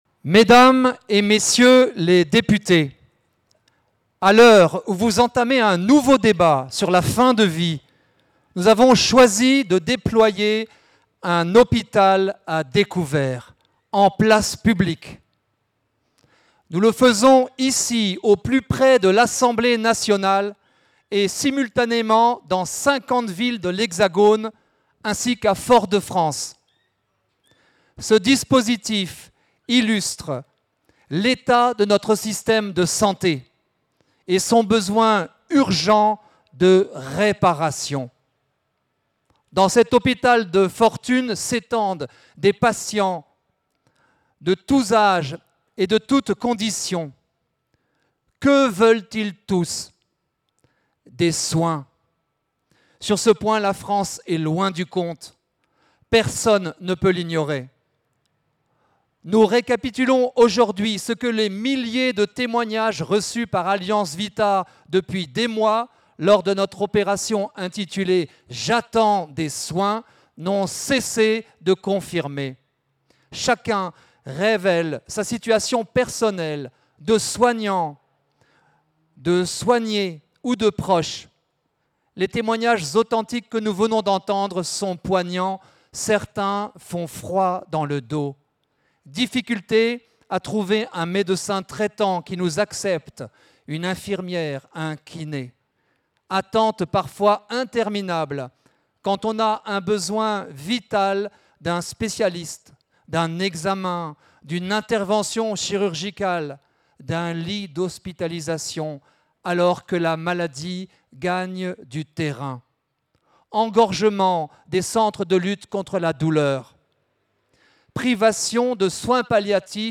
Revivez notre mobilisation Lundi 12 mai, des happenings ont eu lieu dans une cinquantaine de villes en France pour réclamer des soins et refuser l'euthanasie.